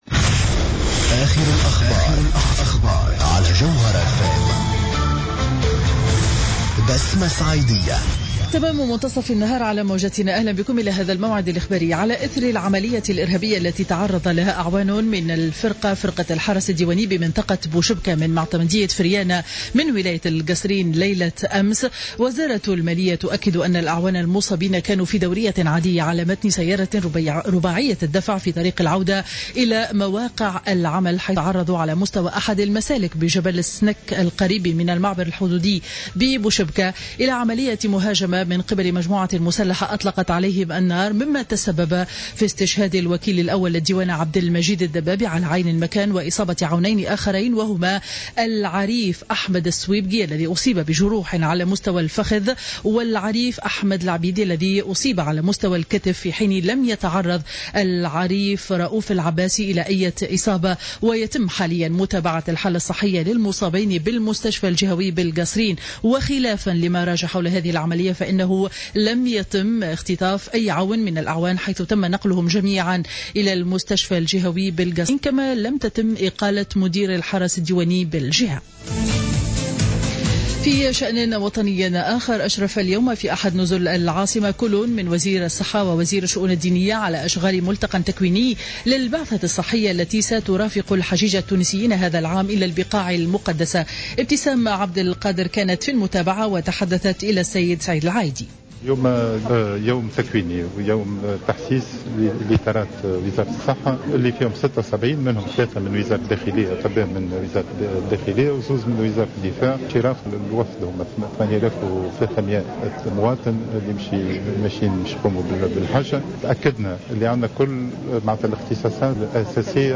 نشرة أخبار منتصف النهار ليوم الاثنين 24 أوت 2015